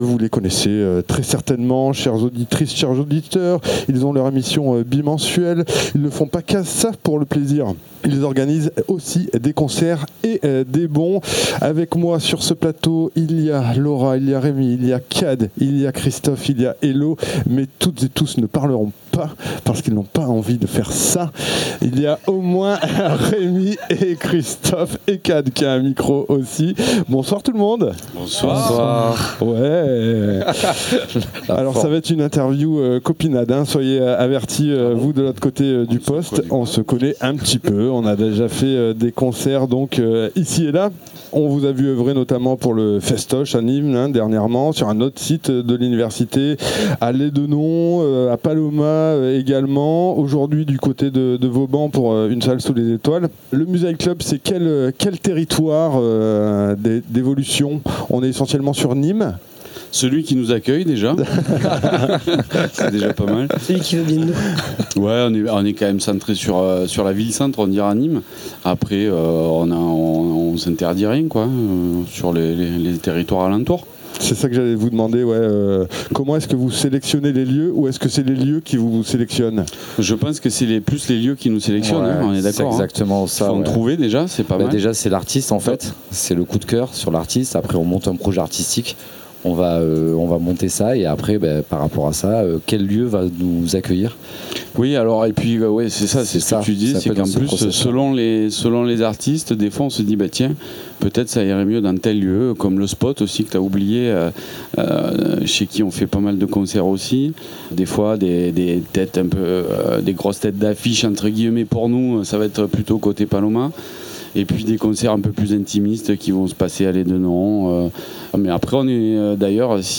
Retour avec le Musight Club sur le plateau de juillet dernier pour le festival Une Salle Sous Les Etoiles, où l'équipe était au quasi complet devant le micro